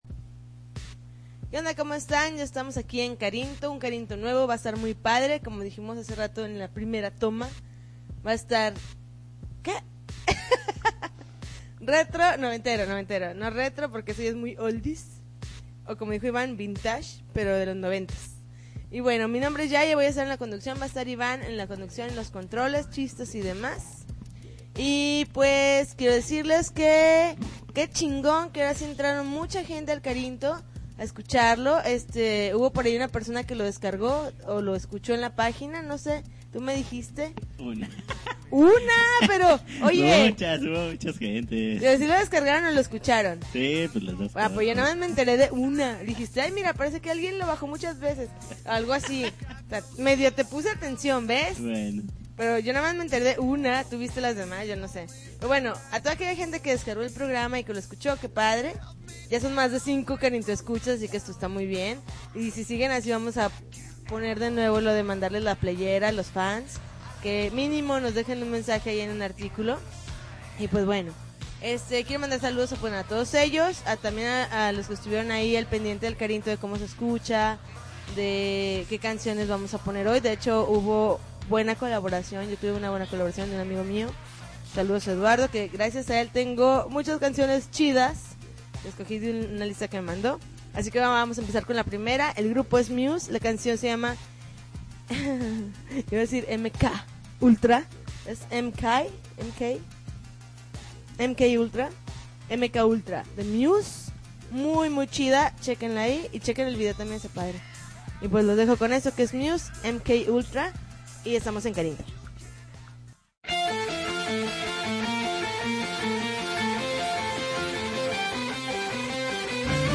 November 8, 2009Podcast, Punk Rock Alternativo